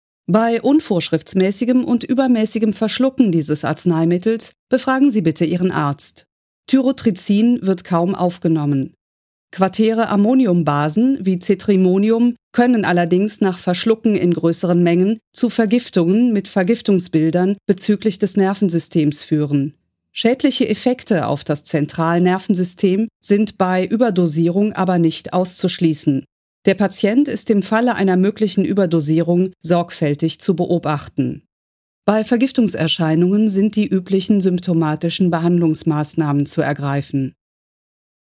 Profi-Stimme, mittleres Alter, Stimmlage Alt, Imagefilme, Werbespots, Hörbücher, Reportagen, Hörspiele, Sachtexte, PC-Spiele, E-Learning, Podcasts, Zeichentrick, Dialekte, Dokumentationen, Synchronisation, Features, Telefonansagen, Hotlines, Kölsch, slawischer Akzent, Mini-Studio, Formate .wav, .mp3
Sprechprobe: eLearning (Muttersprache):
female, middle-aged voice over talent